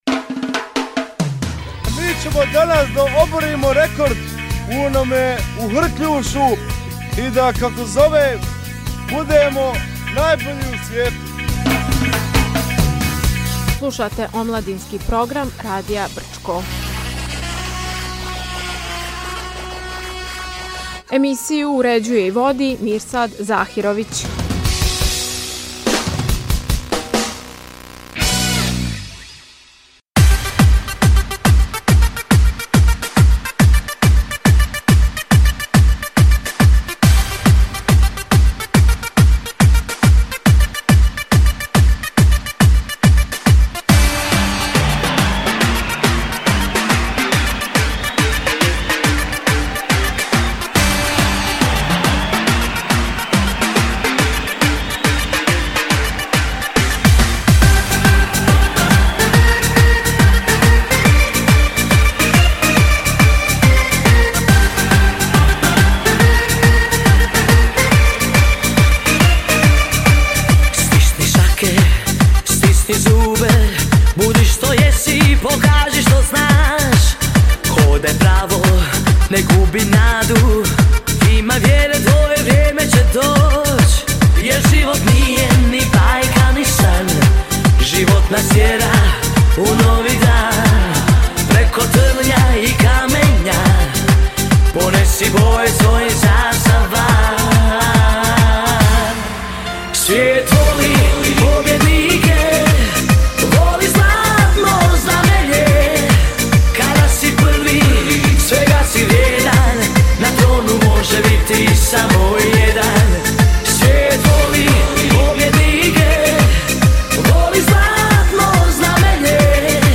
Gošća Omladinske emisije